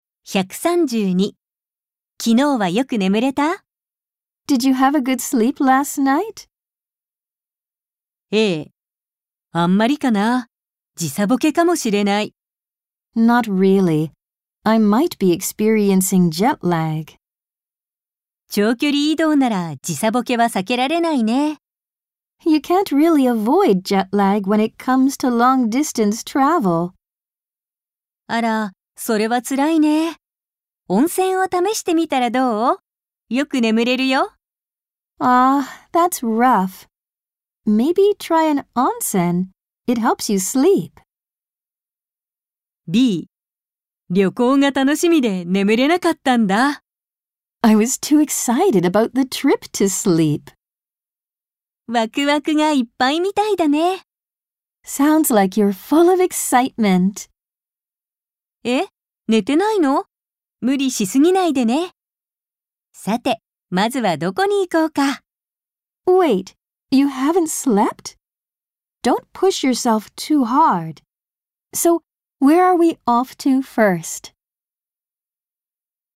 ・ナレーター：アメリカ英語のネイティブ（女性）と日本語ナレーター（女性）
・スピード：ナチュラル